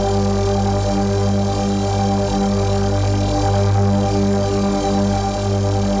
Index of /musicradar/dystopian-drone-samples/Non Tempo Loops
DD_LoopDrone1-G.wav